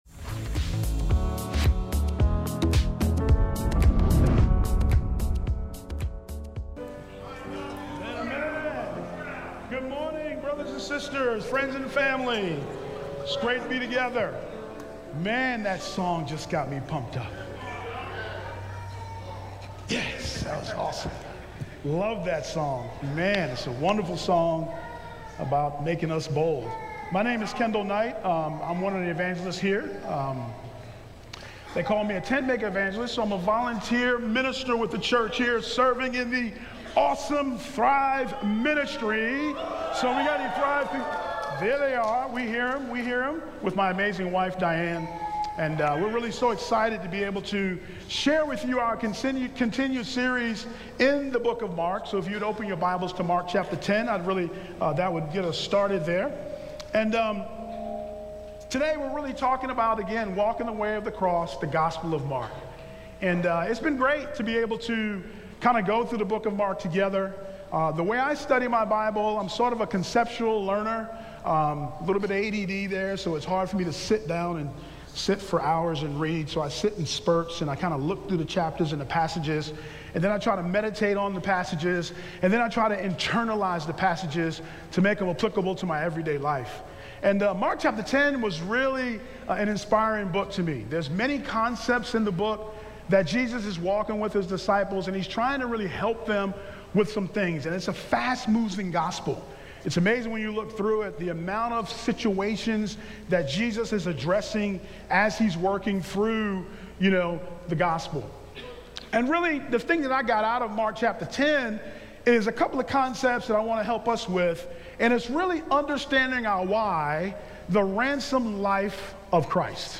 Sermons | North River Church of Christ